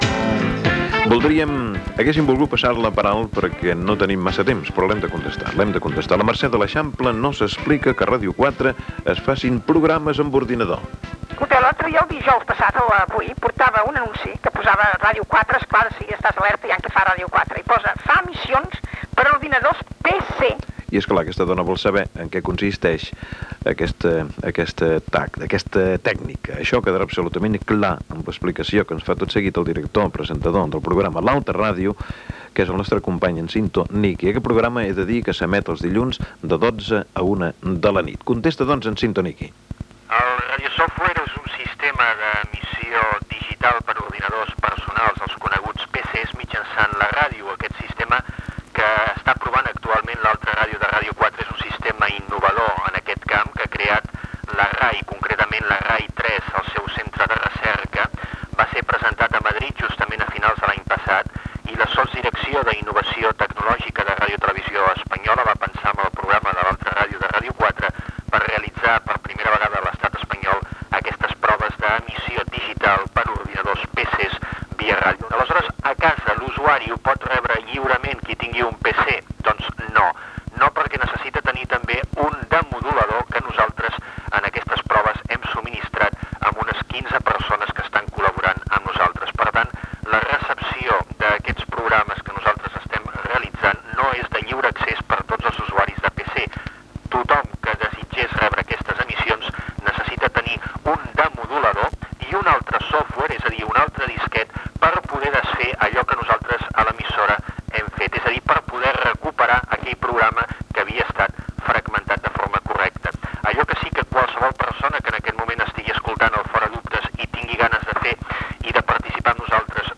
respon al dubte sobre les emissions de Radiosoftware per a PC. Gènere radiofònic Participació